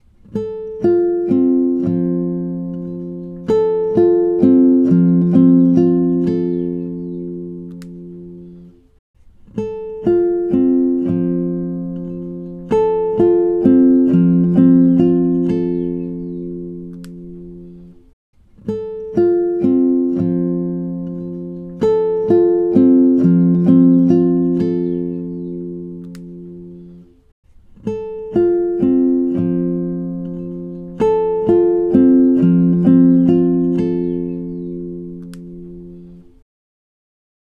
Default Mic preamp comparison
I recorded two mono channels, each through a different preamp into the same
All things were as equal as I could make them, other than the preamps.
About 9 seconds in, then three more times alternating preamps.
One seems to give a little more twang off the higher strings than the other.